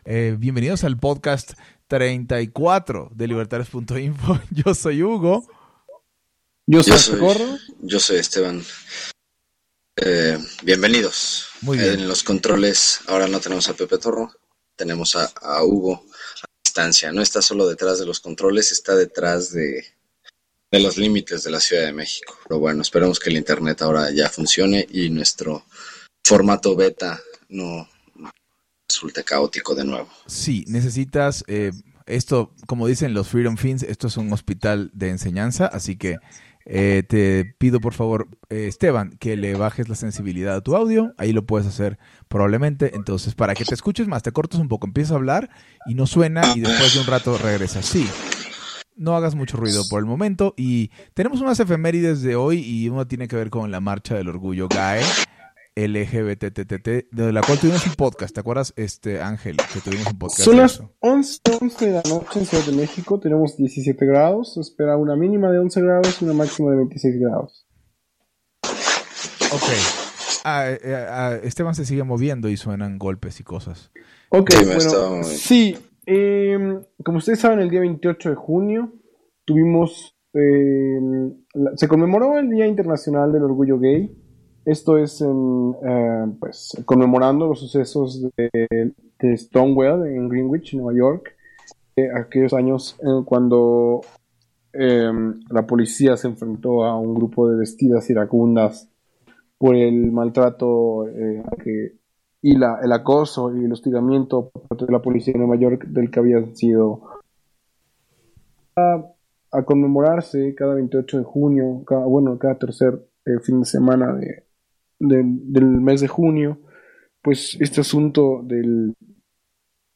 Hacemos nuestra primera prueba de transmisión en vivo, que salió accidentada; mientras tanto, discutimos sobre Ed Snowden. También discutimos si el matrimonio gay estatal es estratégicamente compatible con el libertarismo.